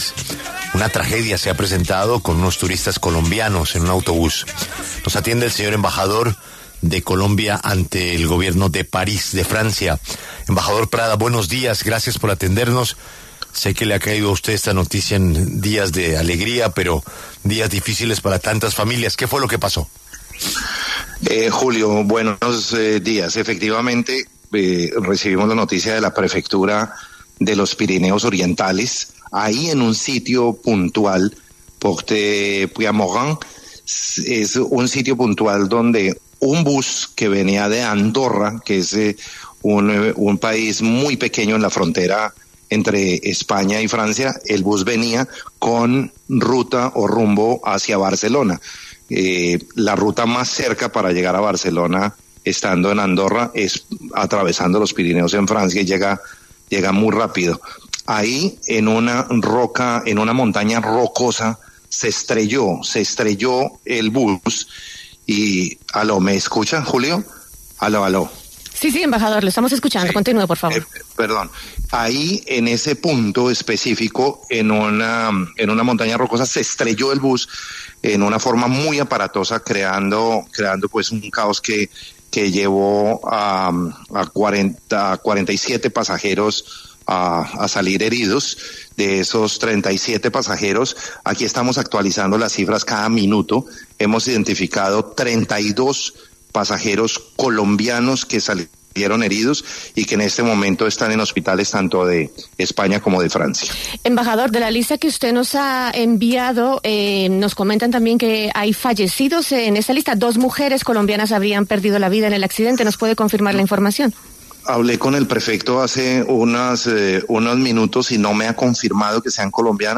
Alfonso Prada, embajador de Colombia en Francia, conversó con La W sobre el accidente en la región de los Pirineos Orientales, que dejó al menos dos muertos.